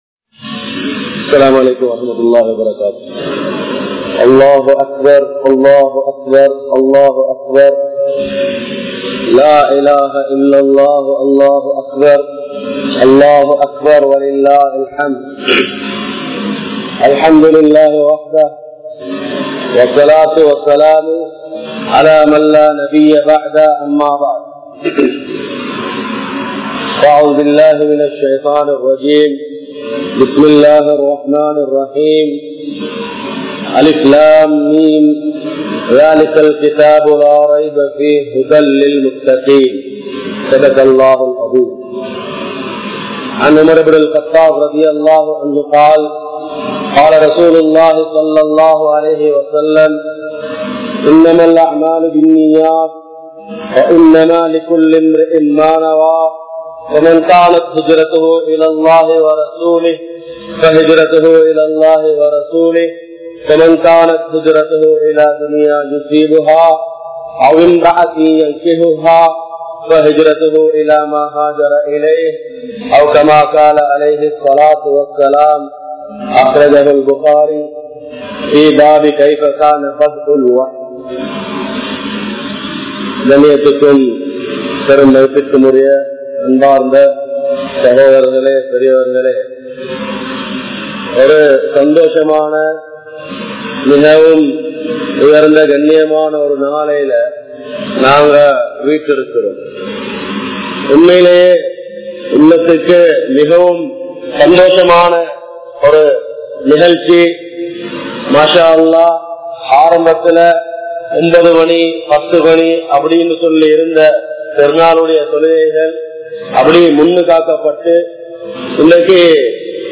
Oru Thaai Pillai Valartha Murai (ஒரு தாய் பிள்ளை வளர்த்த முறை) | Audio Bayans | All Ceylon Muslim Youth Community | Addalaichenai
Muhiyadeen Jumua Masjith